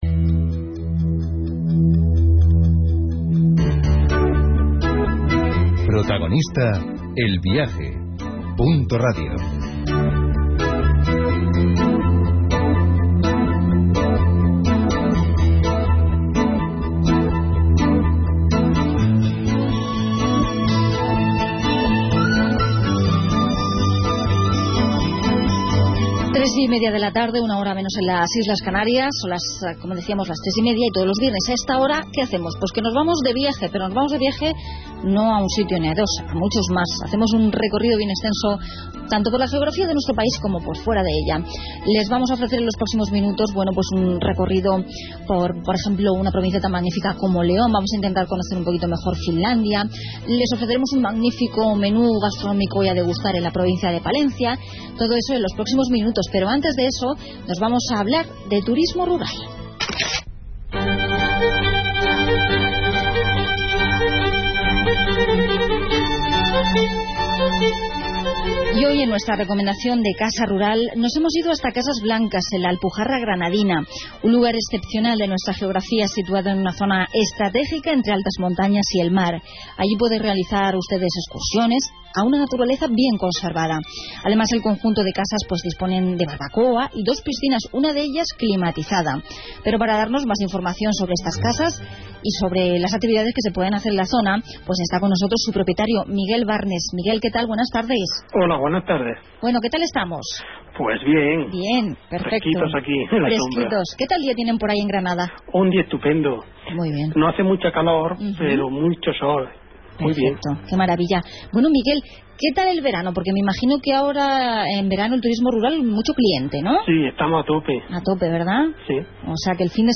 Entrevista de Punto Radio a Casas Blancas